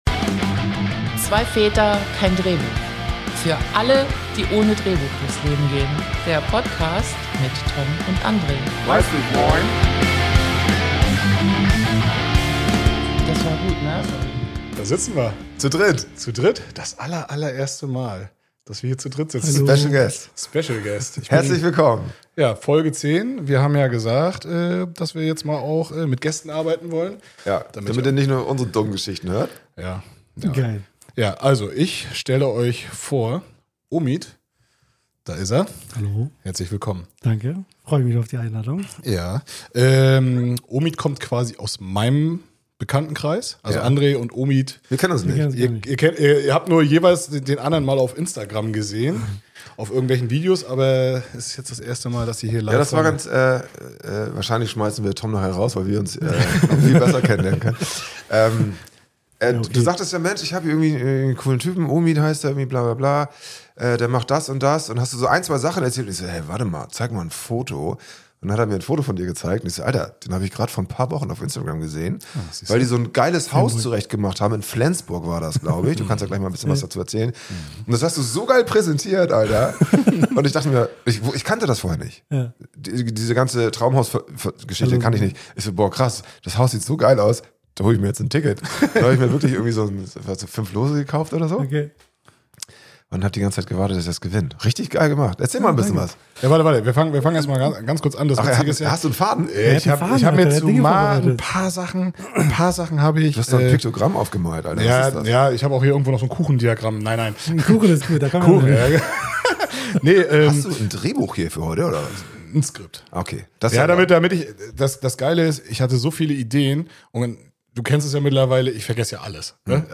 Beschreibung vor 7 Monaten Das war sie also, unsere erste Folge zu dritt.